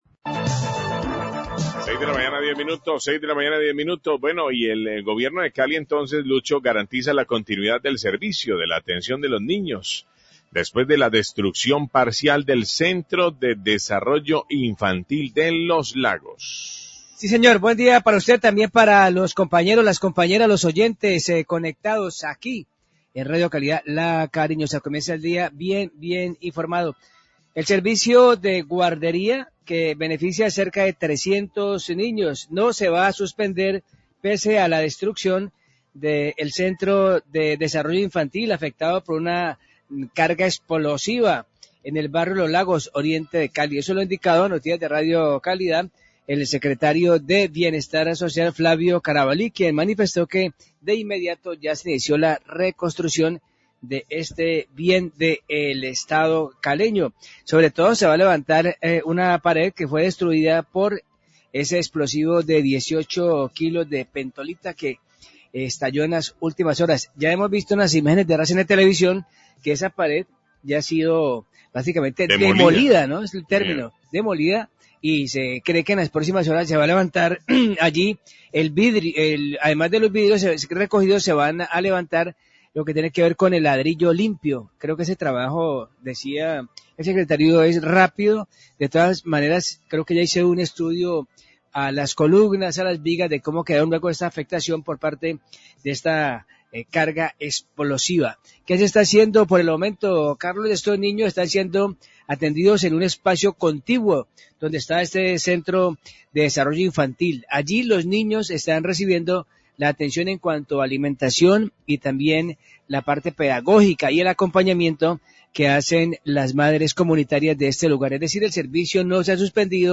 Secretario de Bienestar Social habla de CDI afectado en atentados, 612am
Radio